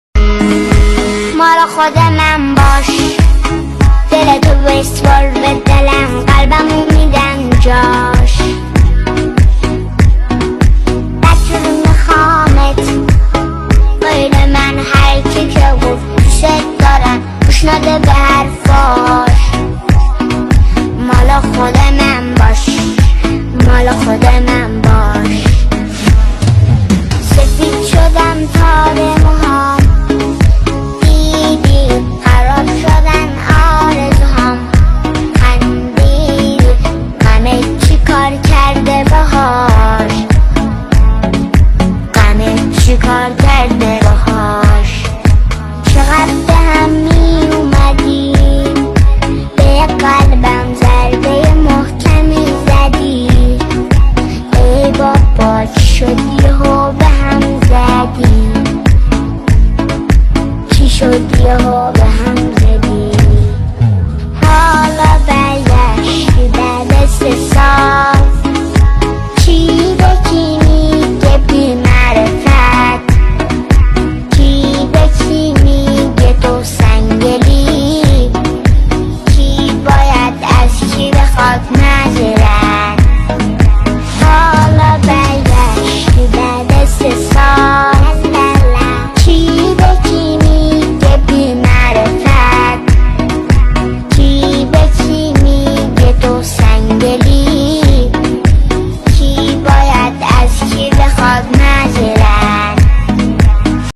ژانر: پاپ
با صدای دختر بچه ورژن جدید معروف اینستاگرامی و تیک تاک